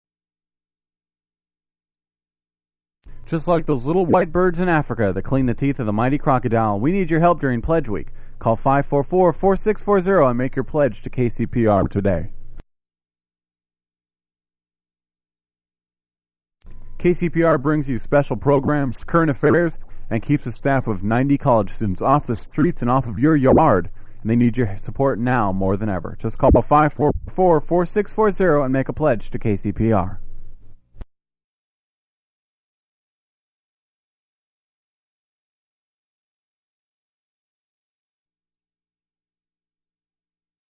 Form of original Audiocassette